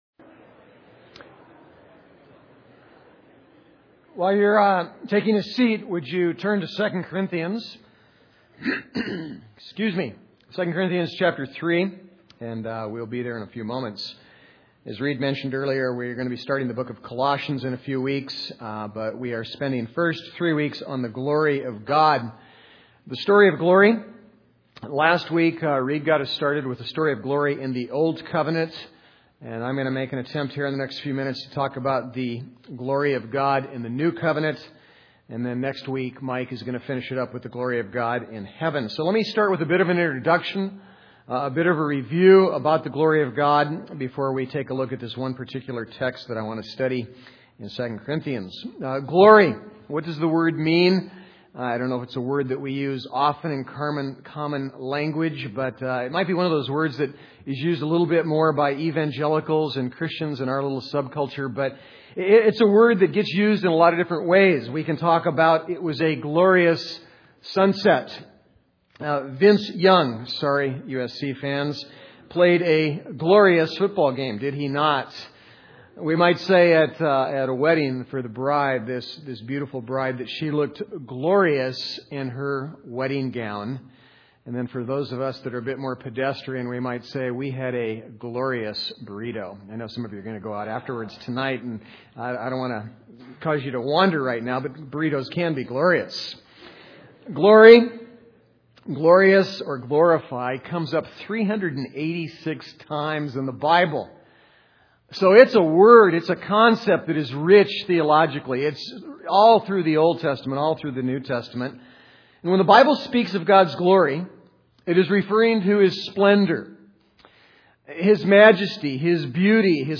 The Story of Gods Glory Service Type: Sunday The Story of Gods Glory « The Story of Gods Glory